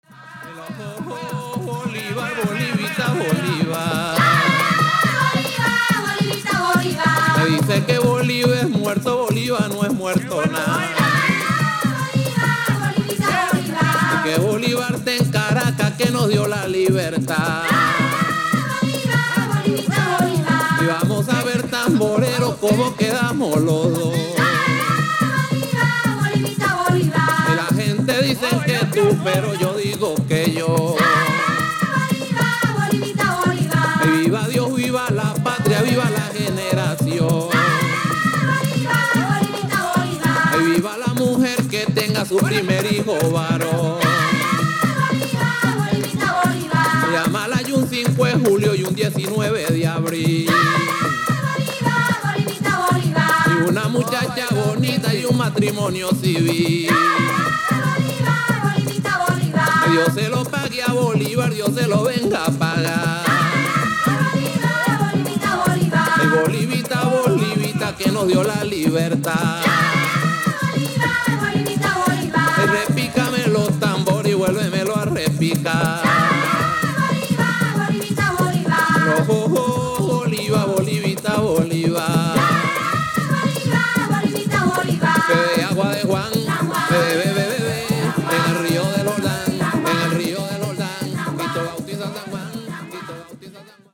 traditional band